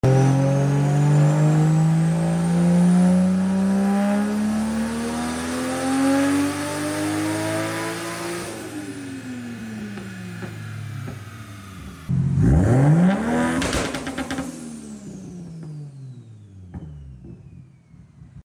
Dyno day for the 1JZ sound effects free download
Stock long block 1jz non vvti/with Head stud’s 6262 single turbo kit